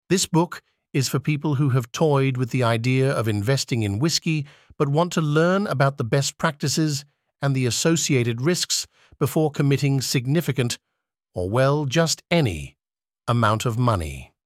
No persze nem én adnám a hangomat hozzá, hanem Daniel az Elevenlabs-től, aki akkora arc, hogy mindössze $11-ért felolvassa nekem a ~3 órányi anyagot, stúdió minőségben, jól érthető brit akcentussal.
Mondjuk mivel igazából egy AI-ról — pontosabban egy AI-hangról — van szó, ezért ezért az árért ezt a minőséget minimum elvárom tőle.